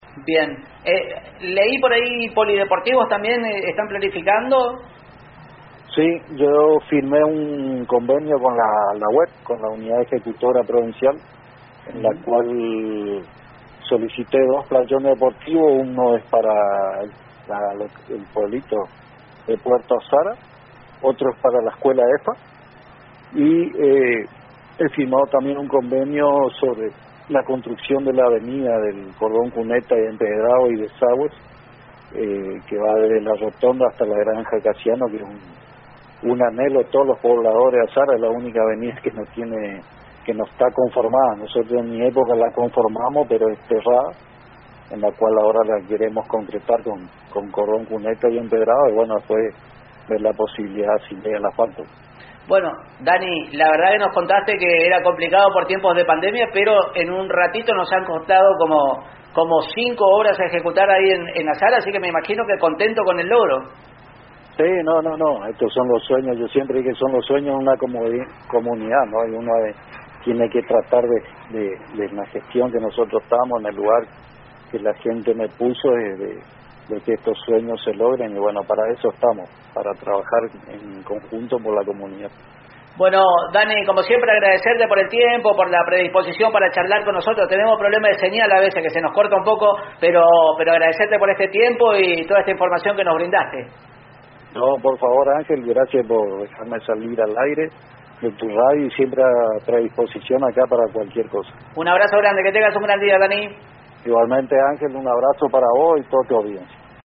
En comunicación telefónica con Radio Elemental y A.N.G.